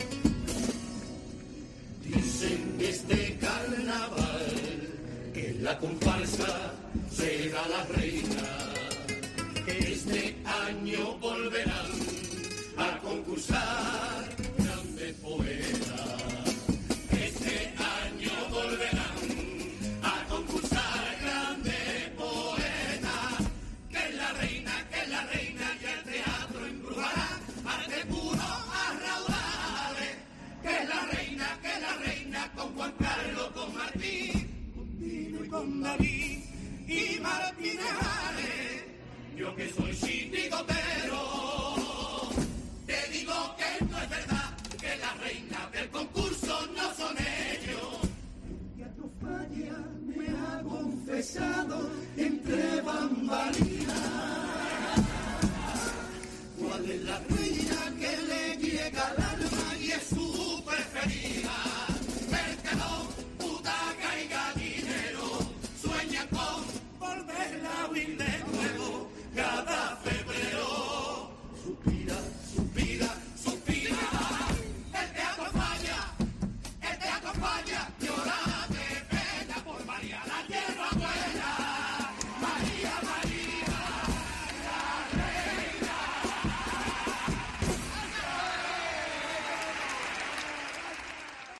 pasodoble